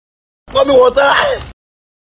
Pour Me Water - Comedy Sound Effect
Pour-me-water-Comedy-sound-effect.mp3